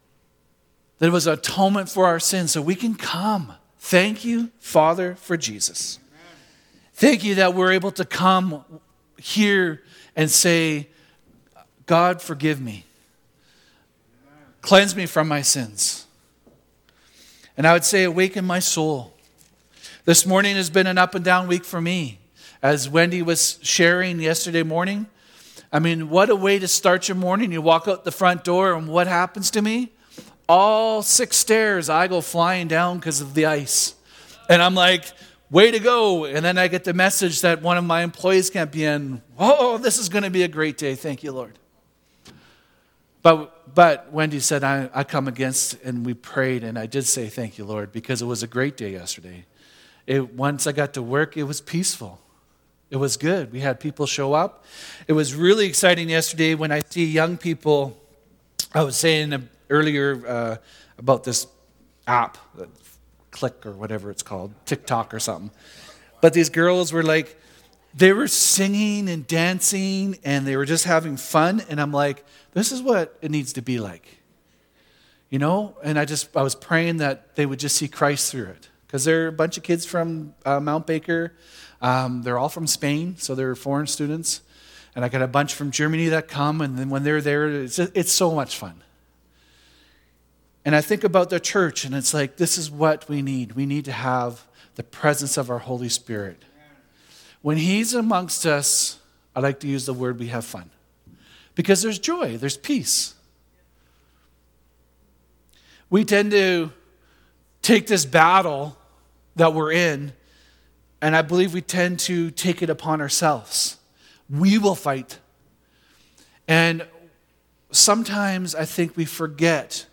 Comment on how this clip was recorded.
Ephesians 6:13-14 Service Type: Sunday Service « Battle Ready